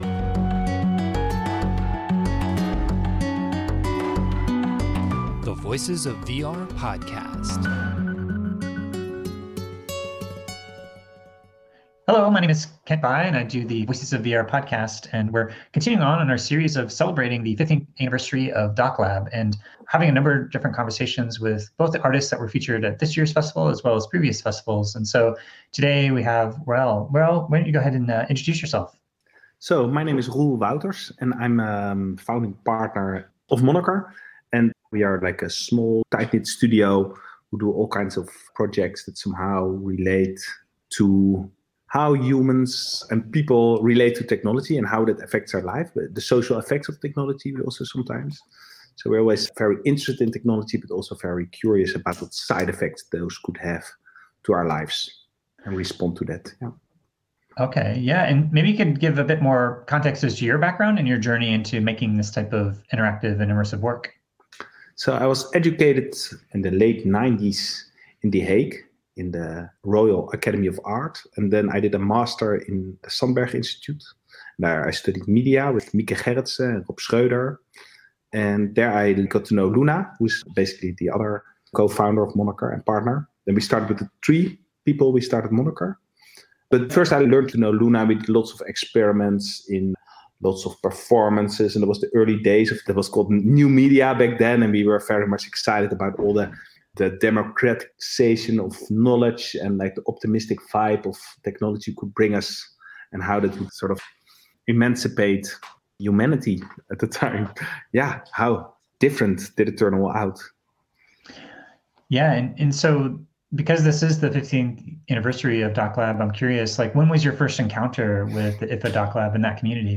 This was recorded on Friday, December 3, 2021 as a part of a collaboration with IDFA’s DocLab to celebrate their 15th year anniversary.